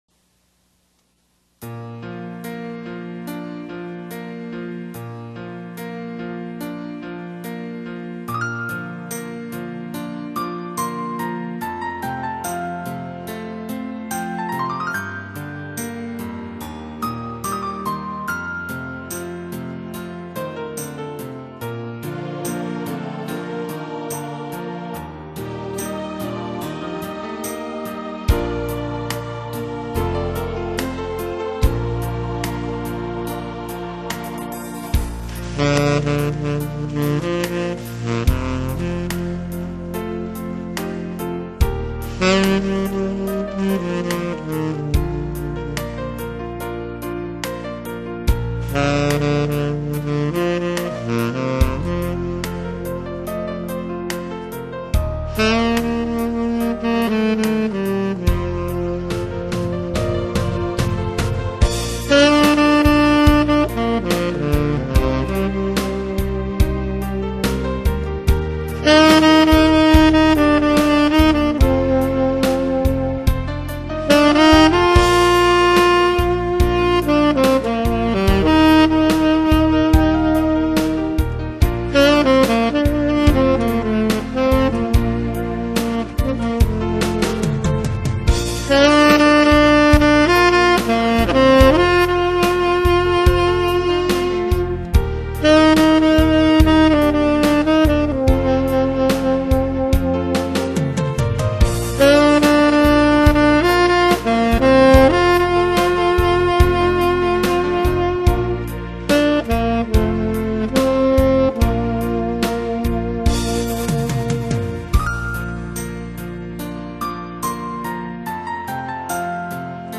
아마추어의 색소폰 연주